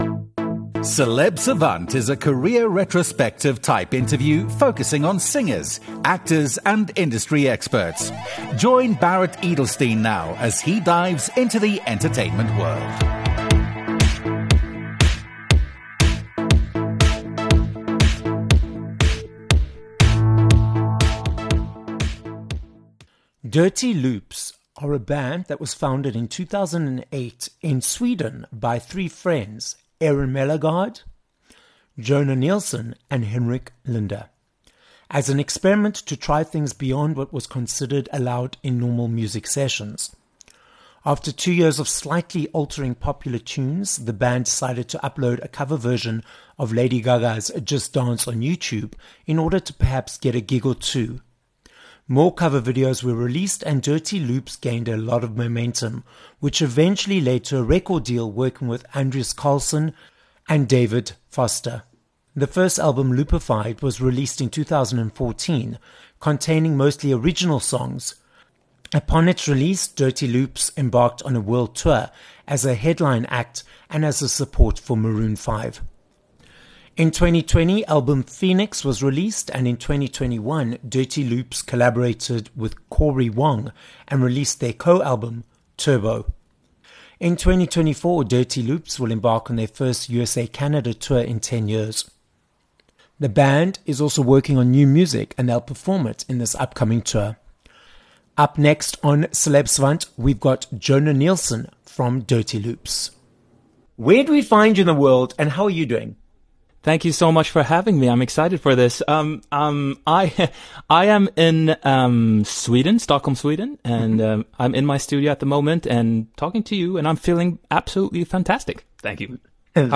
27 Feb Interview with Jonah Nilsson (Dirty Loops)
We head to Sweden on this episode of Celeb Savant, as we are joined by singer, songwriter and musician, Jonah Nilsson from the band Dirty Loops. We get the background story to the band's name, how Jonah and the band were just having fun creating music and were surprised by their successful music career, and more.